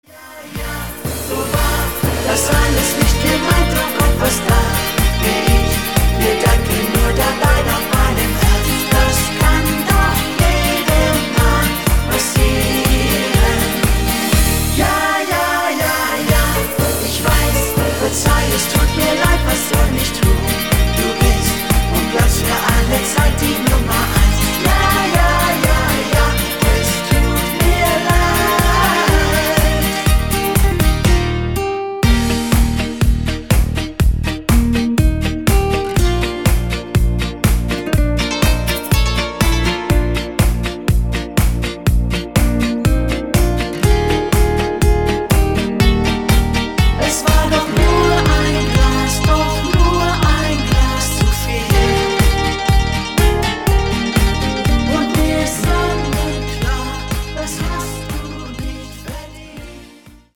Rhythmus  Discofox
Art  Deutsch, Schlager 2020er